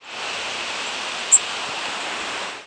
Flight call description A buzzy "tzzit". Pitch variable.
Fig.1. New Jersey September 15, 2001 (MO).
Bird in flight.
The frequency track was single-banded and typically level but occasionally slightly rising or descending.